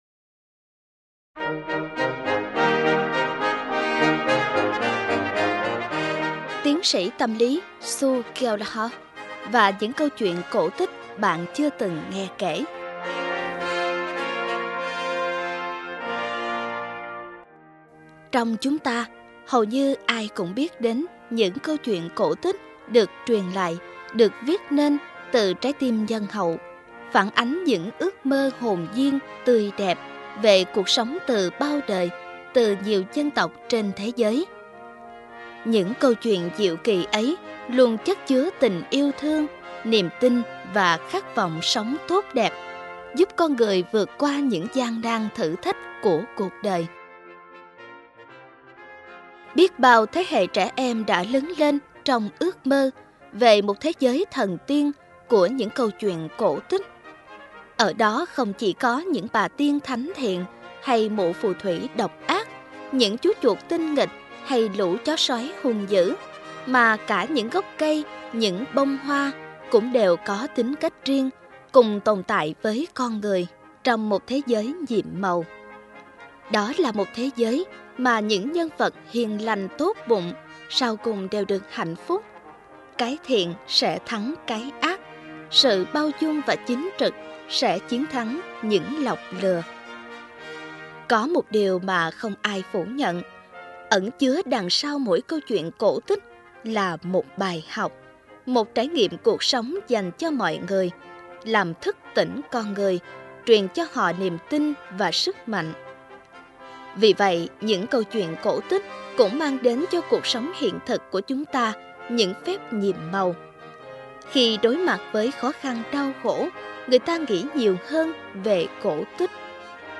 Sách nói Những Câu Chuyện Không Phải Là Cổ Tích - Sue Gallehugh - Sách Nói Online Hay